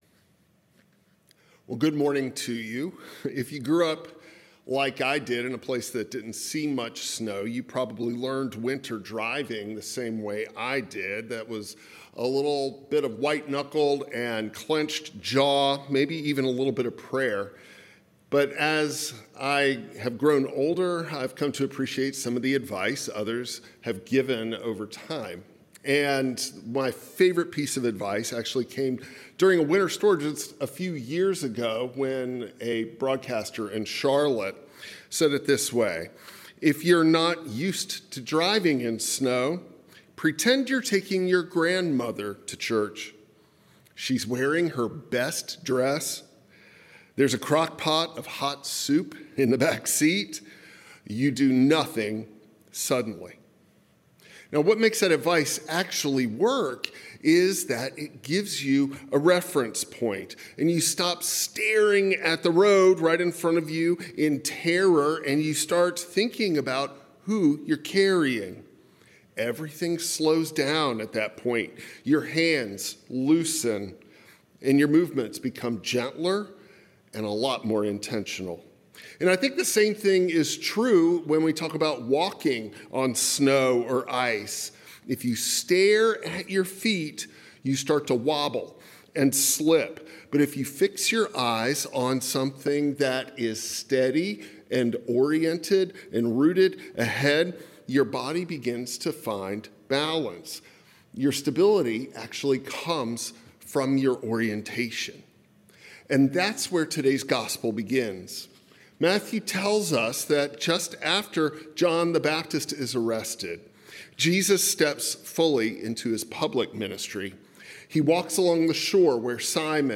Matthew 4:12–23 Service Type: Traditional Service When the ground is slippery